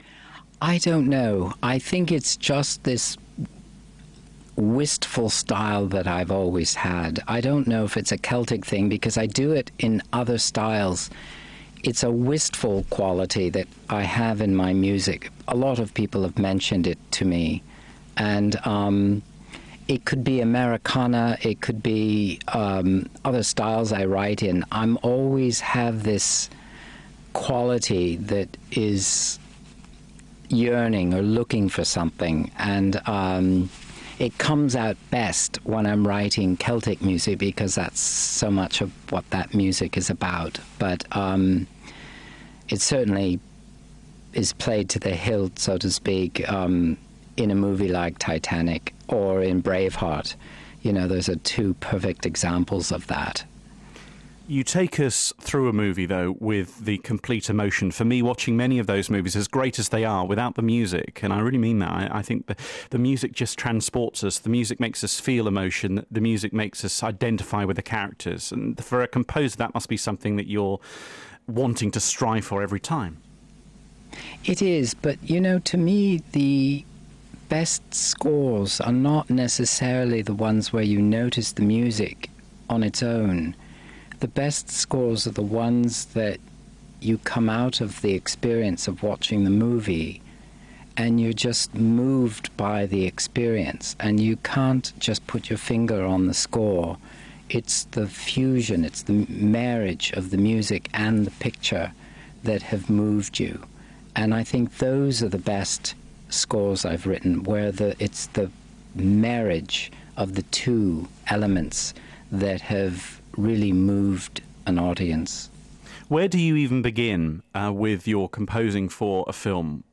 JAMES HORNER (Film composer) ARCHIVE Interview